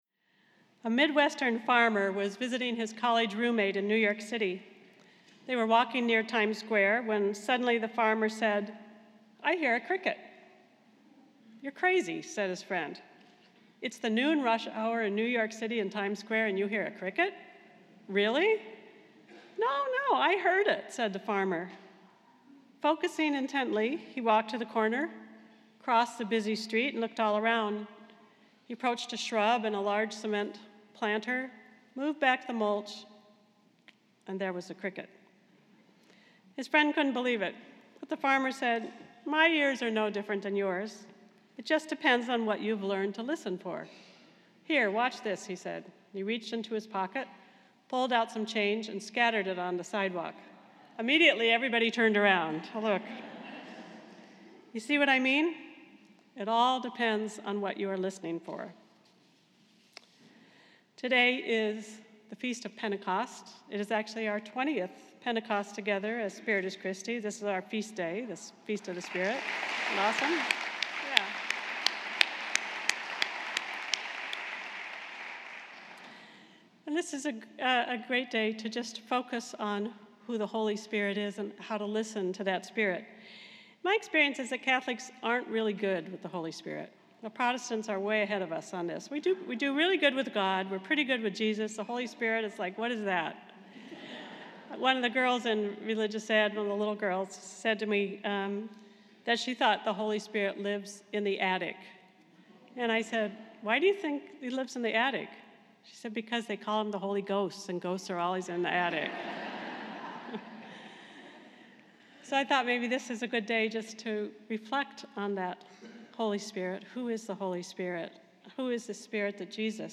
Today Spiritus Christi celebrates its feast day, Pentecost. It is their 20th Pentecost together.